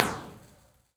Dipway Arch, Central Park
Brick, asphalt, concrete, stone.
Download this impulse response (right click and “save as”)